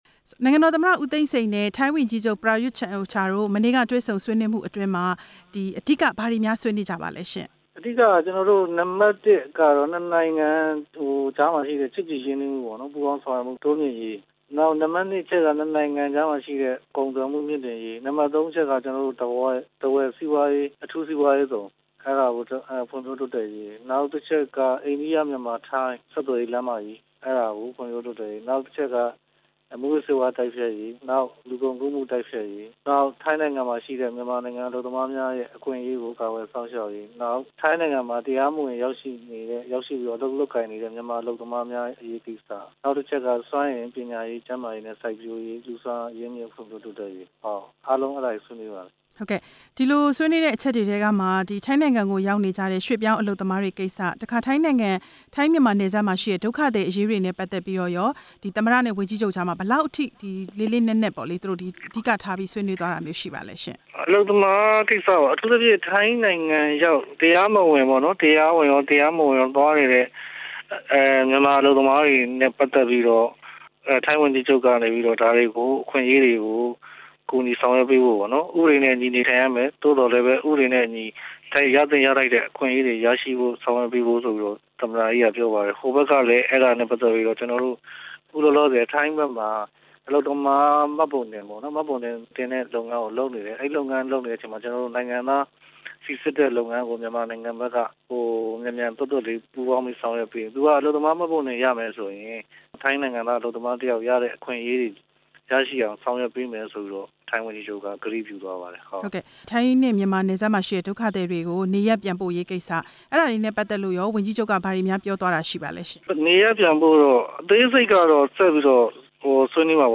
သမ္မတရုံးအကြီးတန်း အရာရှိတစ်ဦးကို မေးမြန်းချက်